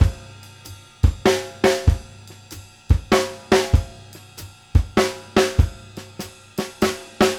CYM GROO.2-R.wav